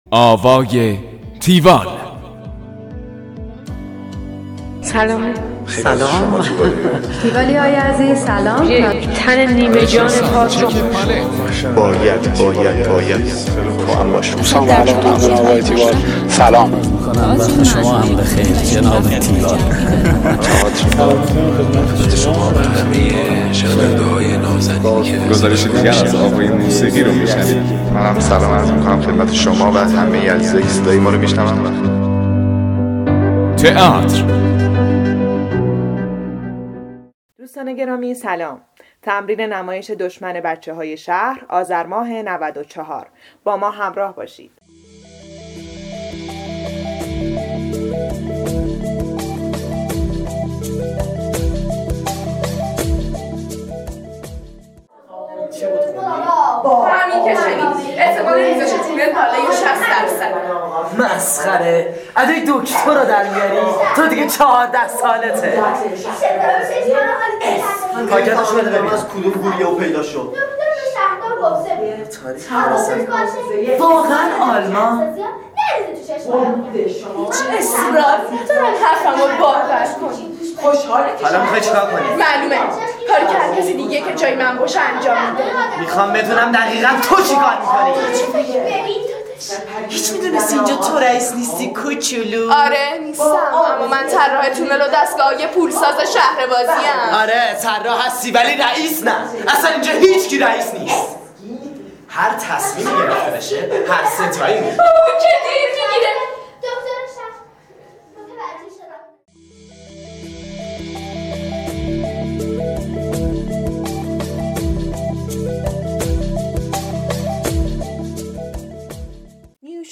گزارش آوای تیوال از نمایش دشمن بچه های شهر